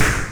just SNARES 3
snarefxldk13.wav